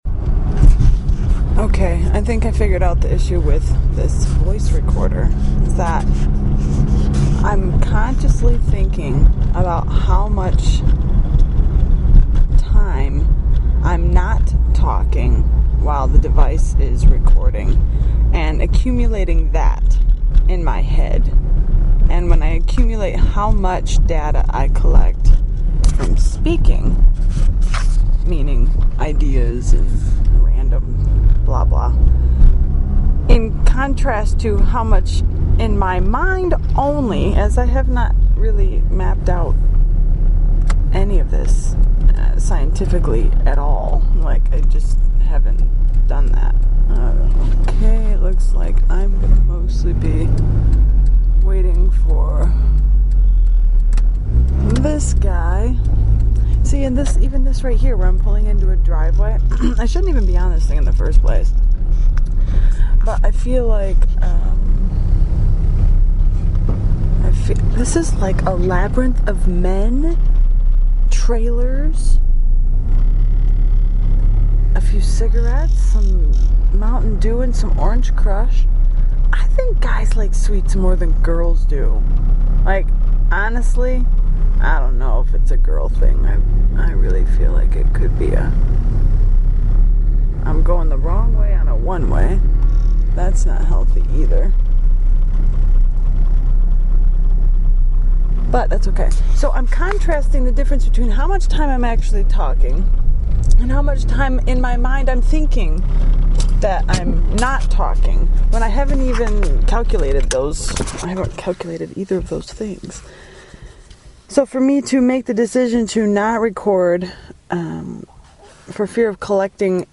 Talk Show Episode, Audio Podcast, New_Clear_Visions and Courtesy of BBS Radio on , show guests , about , categorized as